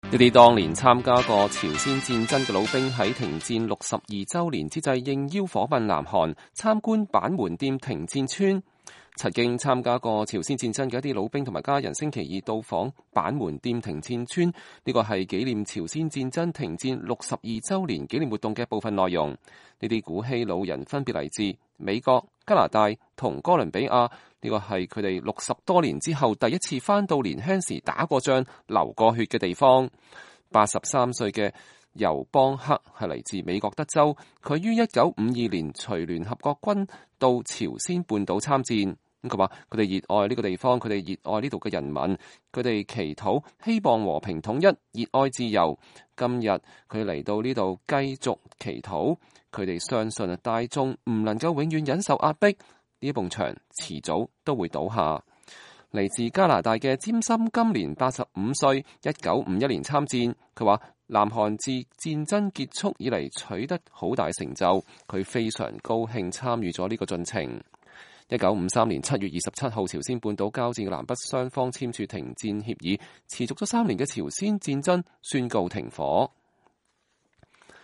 2015-07-28 美國之音視頻新聞:韓戰退伍軍人訪問板門店停戰村